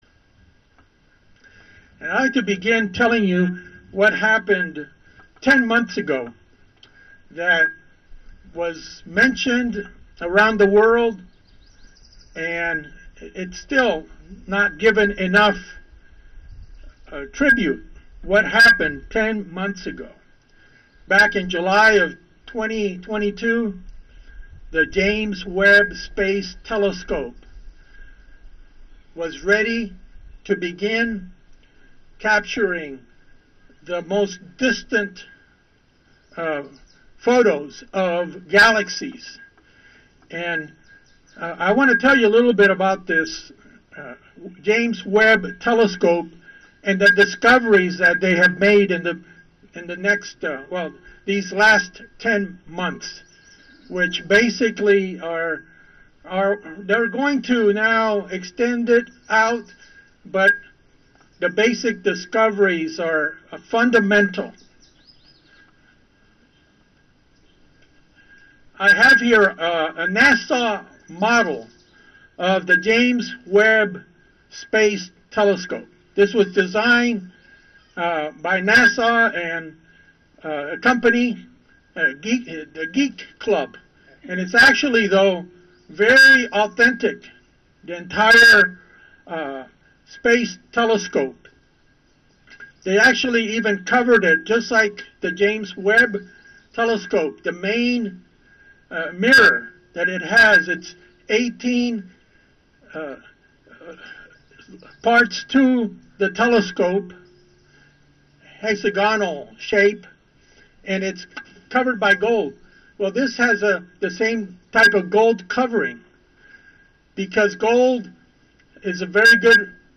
During our biannual Sabbath-In-The-Park, this message discusses the marvels of God's Creation as evidenced by pictures we receive from the deep-space James Webb telescope.